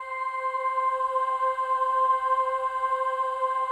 PAD 50-5.wav